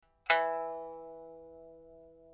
pipa5.mp3